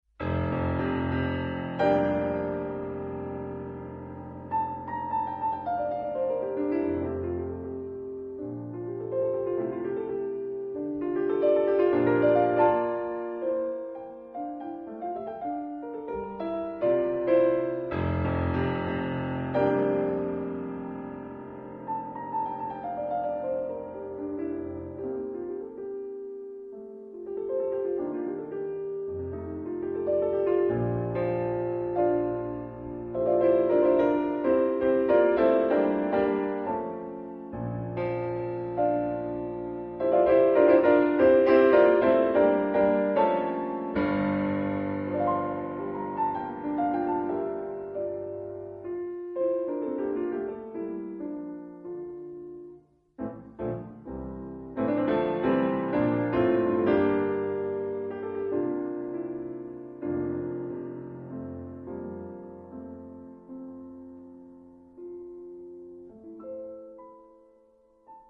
まさに「中くらい」、ゆとりのある心地よいテンポを感じることができたでしょうか。
・演奏の速さは♩=７０から１００くらいの間をゆらゆら（モデラートの速さが７０〜１２０あたりの数値、ほぼフル活用）
美しい旋律そのものに加え、テンポの揺れにより、一層清らかなキラキラが惹き立ちます。